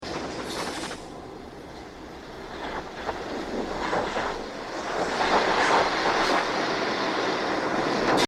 Play, download and share Sizzling flicker original sound button!!!!
012-sizzling-flicker.mp3